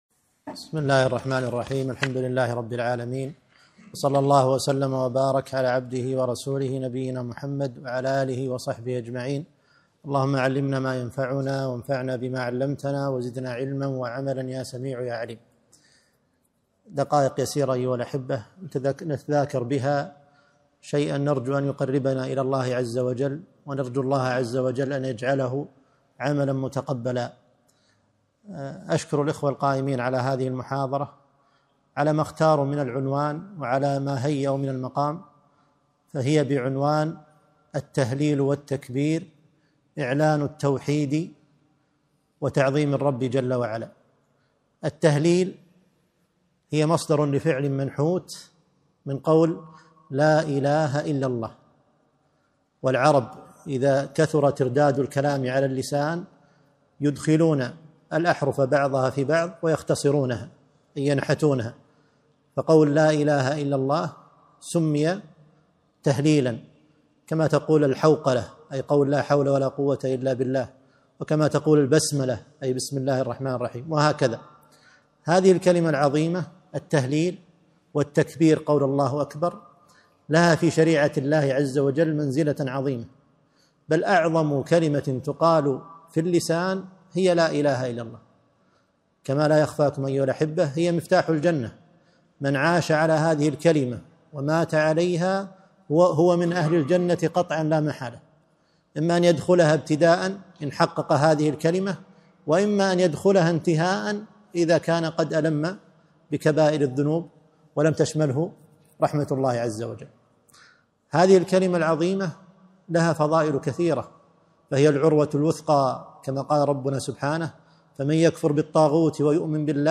كلمة - التهليل والتكبير